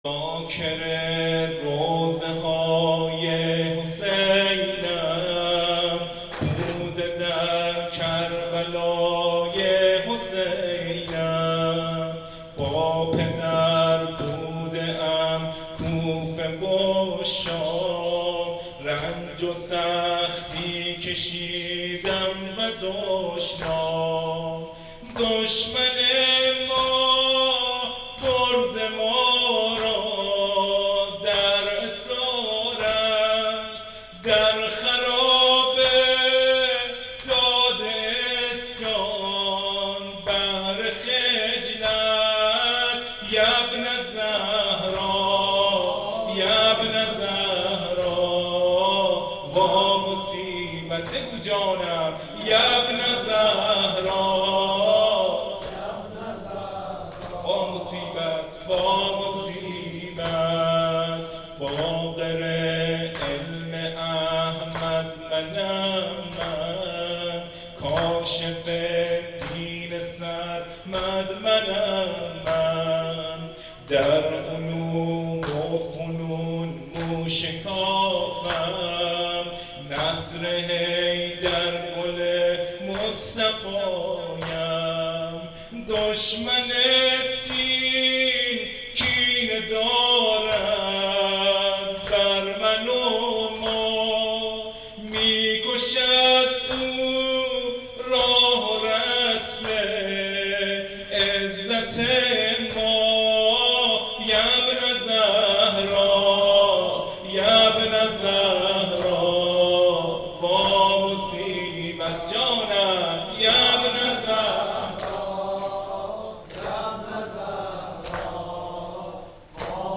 نوحه شهادت امام باقر